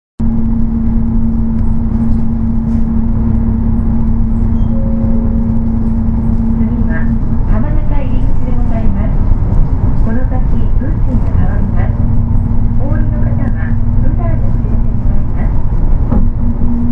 音声合成装置 指月電機製作所